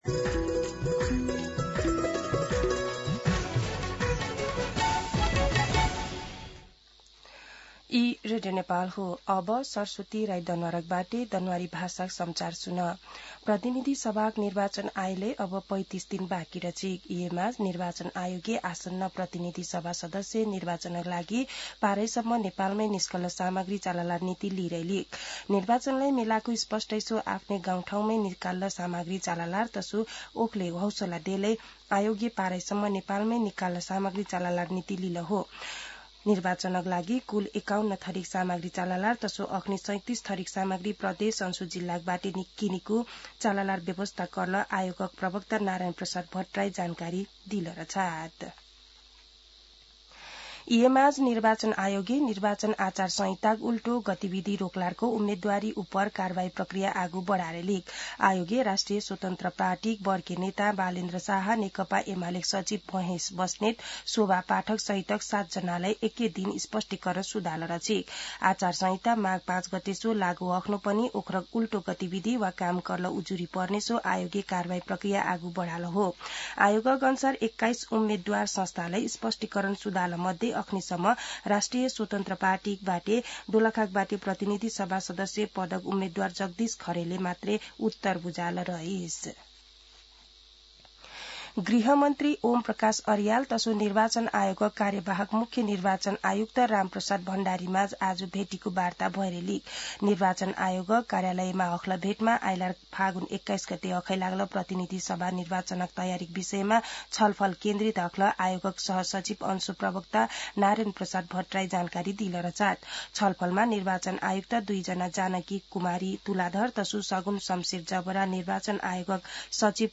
दनुवार भाषामा समाचार : १५ माघ , २०८२
Danuwar-News-10-15.mp3